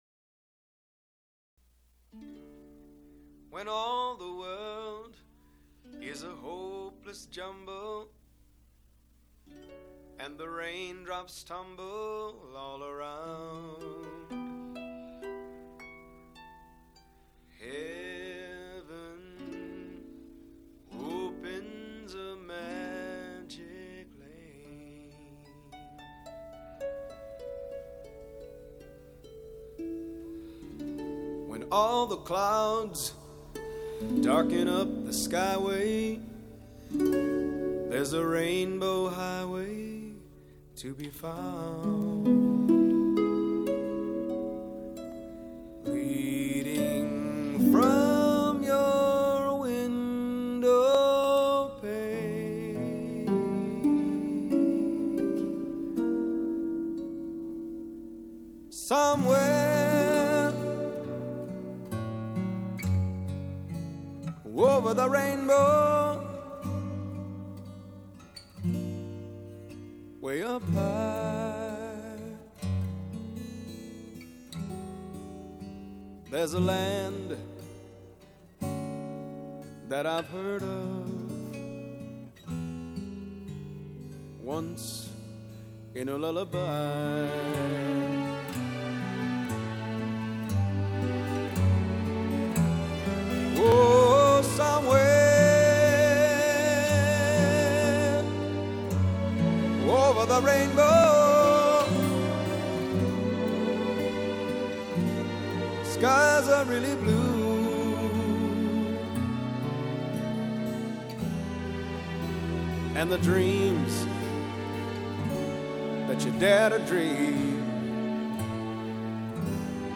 It starts out soft and distant but wait.